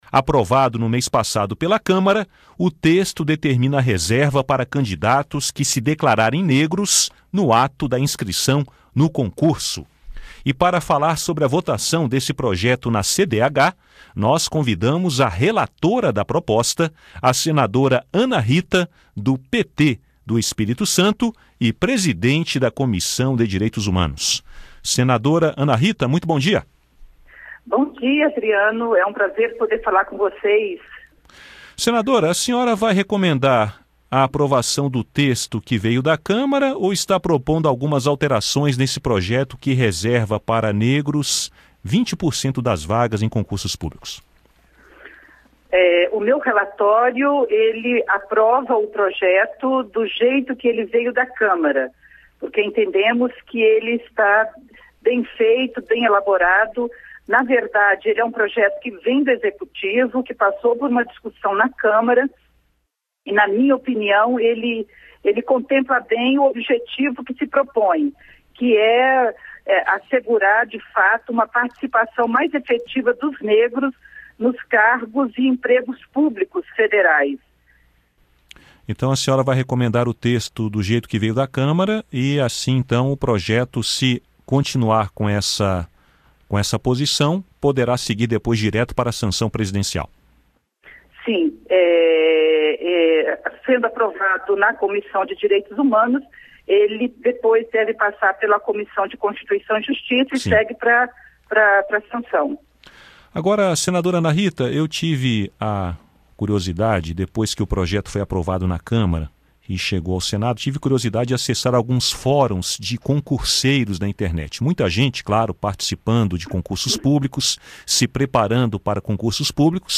Cotas em concursos: Relatório da CDH deve manter texto da Câmara Entrevista com a relatora da proposta, senadora Ana Rita (PT-ES).